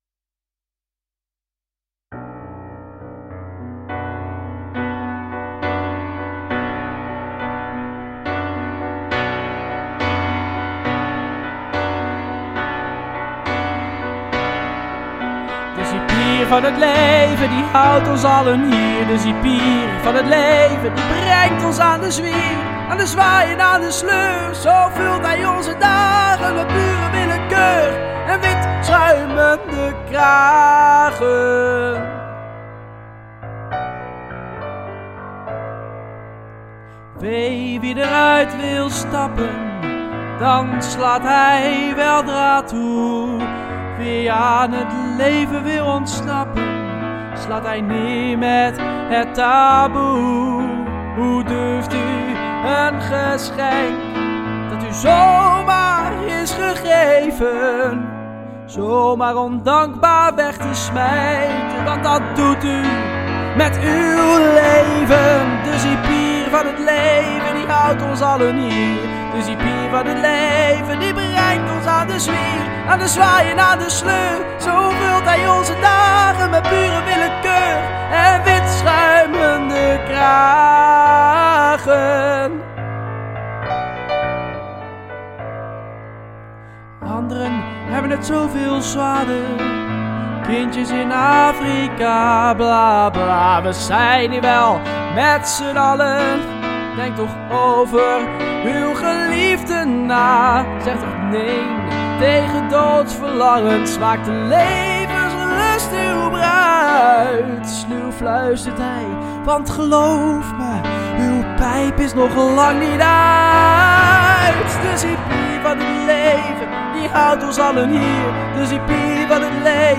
Een krachtig strijdlied.